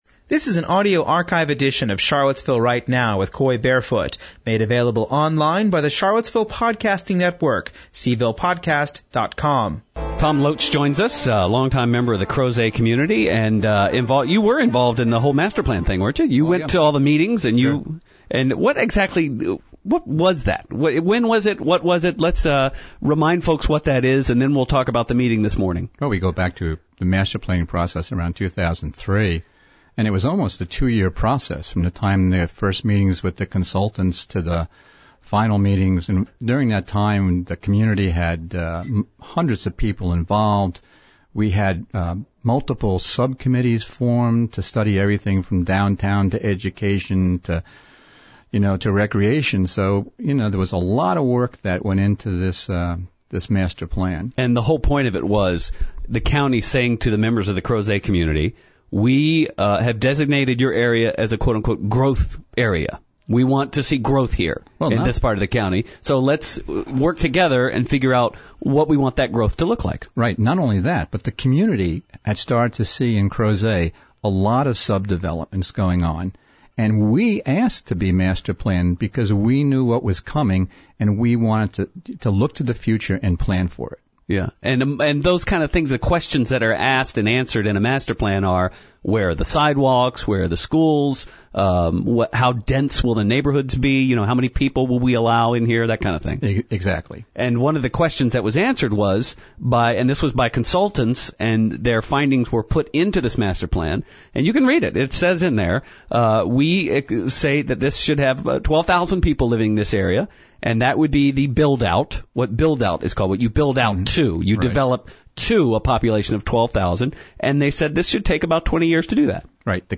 This interview is from the July 5, 2006 edition of the show. https